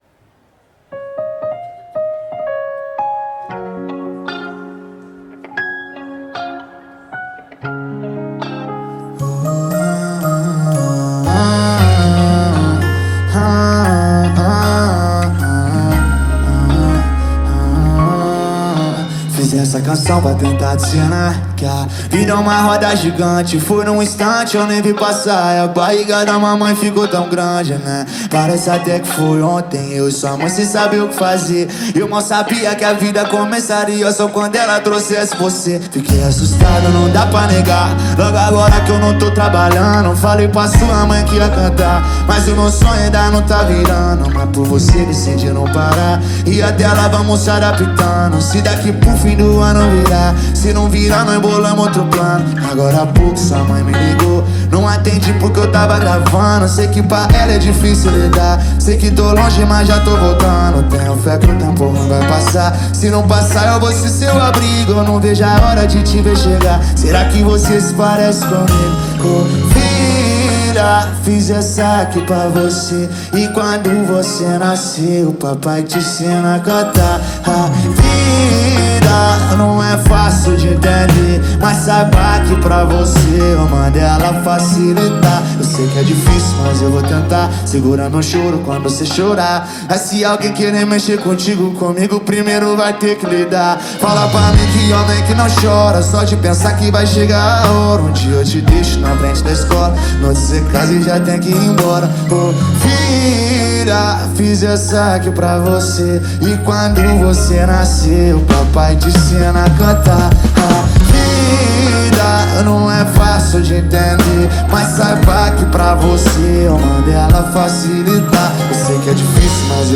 Baixar Funk
regência e Violão
Baixo / Bass Synth
Bateria
Teclados
Guitarra